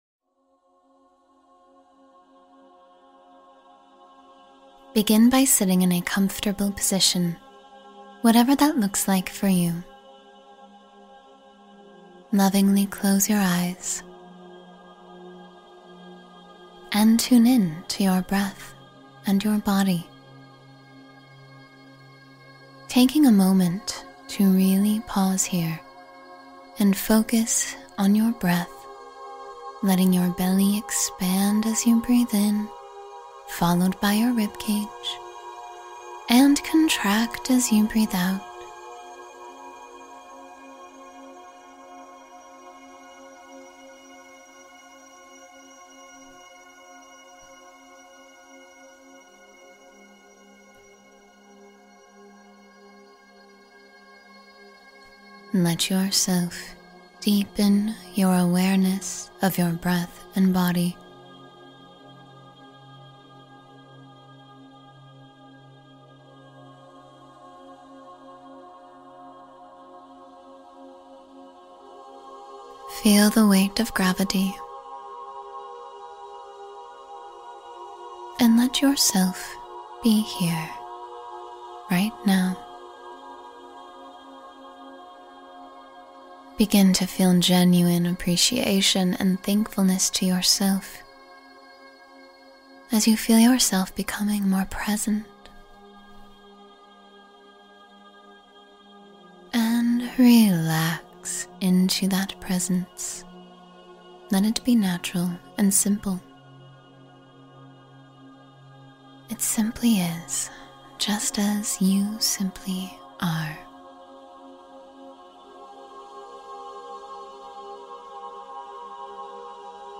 Guided Meditation for Spiritual Expansion — Open Yourself to Infinite Growth